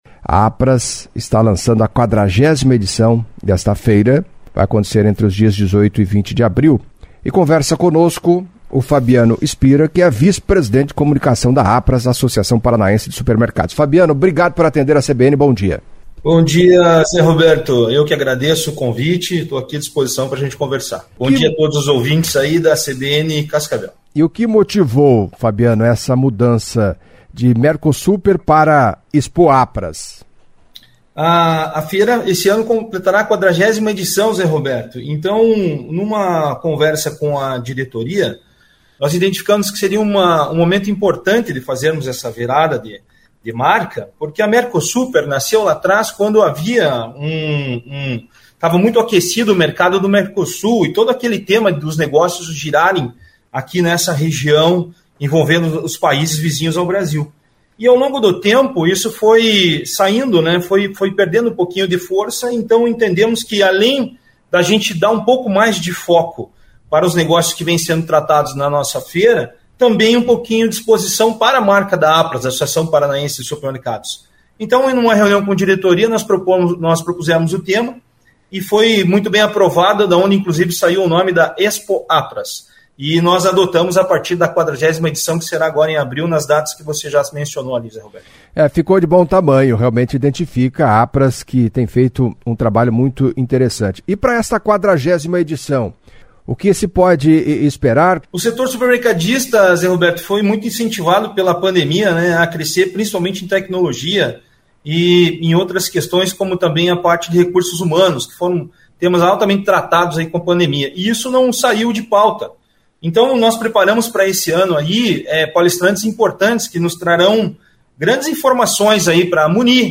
Em entrevista à CBN Cascavel nesta segunda-feira (13)